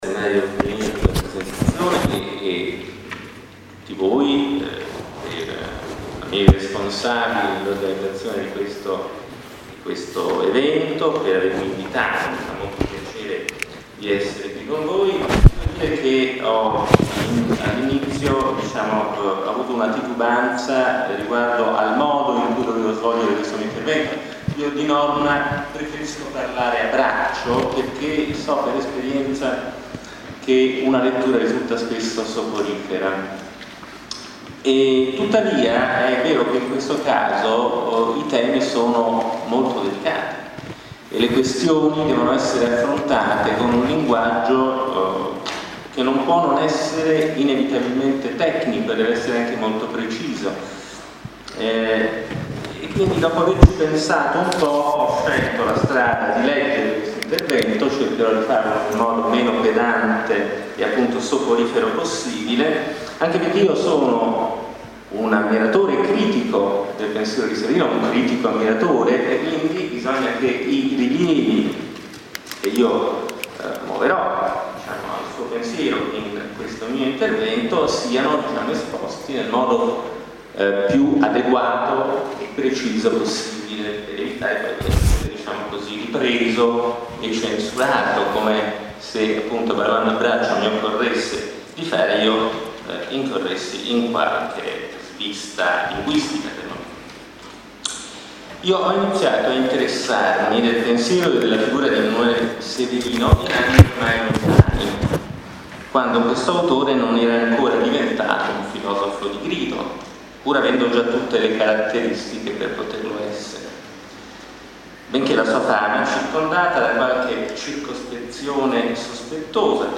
IL DESTINO DELL’ESSERE, dialogo con (e intorno al pensiero di) EMANUELE SEVERINO, Venezia, Università Ca’ Foscari, Ca’ Dolfin, aula magna Silvio Trentin, 29 e 30 maggio 2012. registrazione delle RELAZIONI